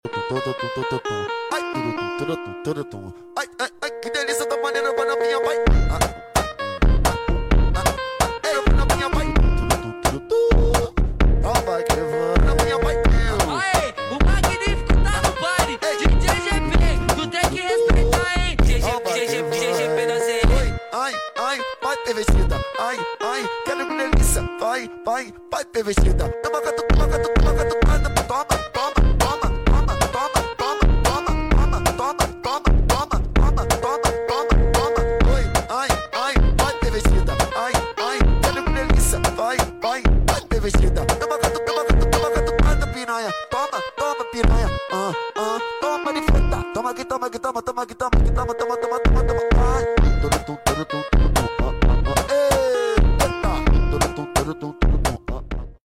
Boxer con motor De pulsar sound effects free download